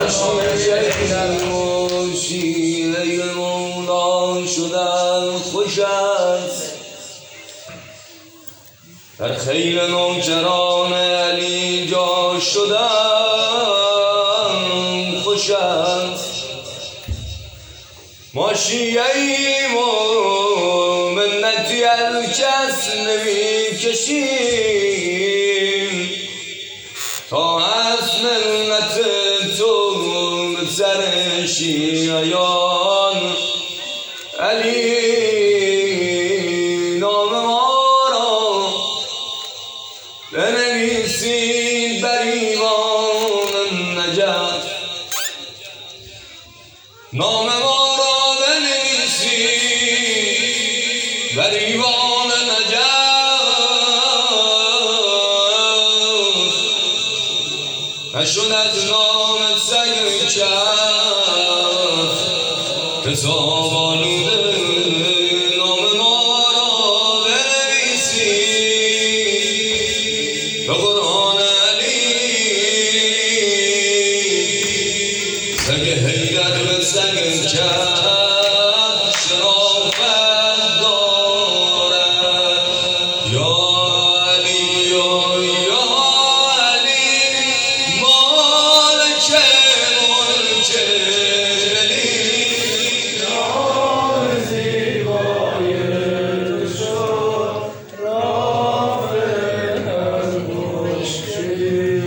هیئت صادقیون زابل
شب اول دهه اول فاطمیه 1442